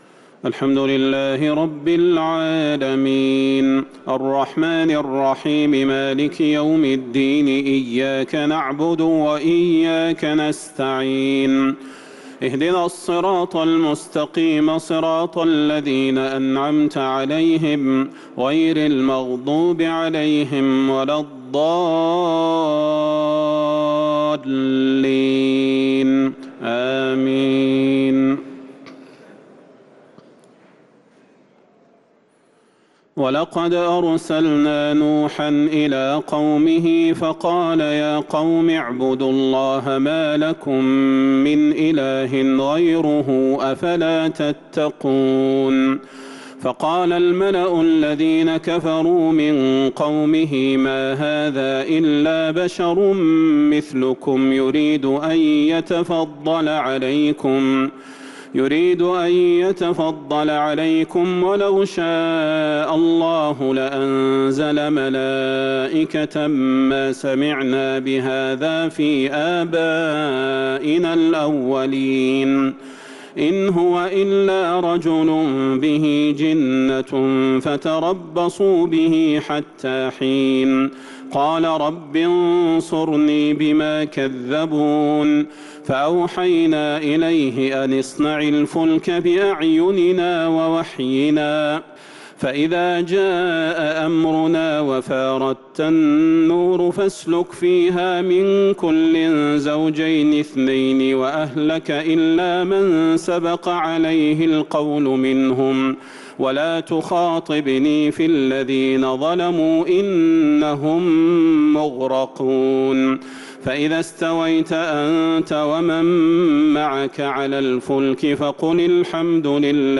تراويح ليلة 22 رمضان 1443هـ من سورة المؤمنون {23-اخرها} | Taraweeh 22th night Ramadan 1443H > تراويح الحرم النبوي عام 1443 🕌 > التراويح - تلاوات الحرمين